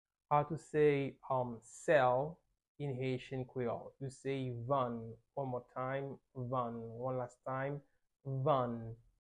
How to say Sell in Haitian Creole - Vann pronunciation by a native Haitian Teacher
“Vann” Pronunciation in Haitian Creole by a native Haitian can be heard in the audio here or in the video below:
How-to-say-Sell-in-Haitian-Creole-Vann-pronunciation-by-a-native-Haitian-Teacher.mp3